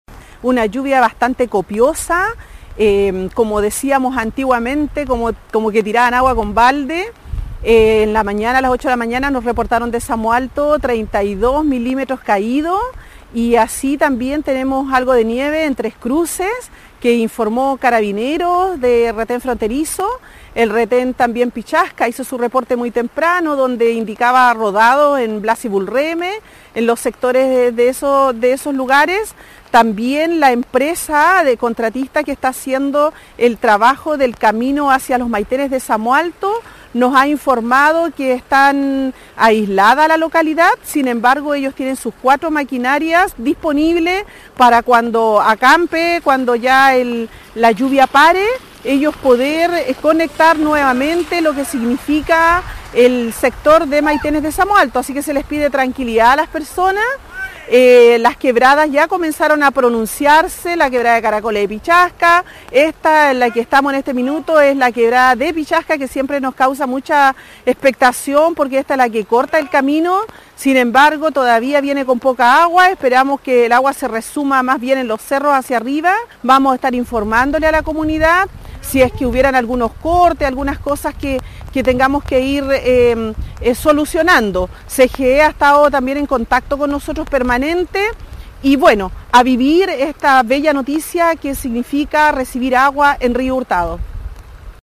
La alcaldesa entregó un balance del paso del sistema frontal.